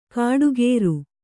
♪ kāḍu gēru